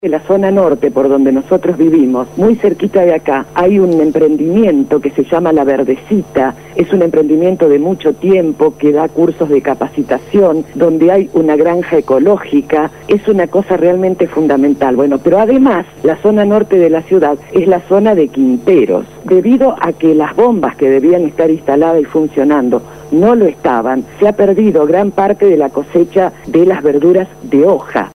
habló esta mañana en el programa Punto de Partida de Radio Gráfica FM 89.3 sobre el panorama de la ciudad de Santa Fe luego de la tormenta de los últimos días.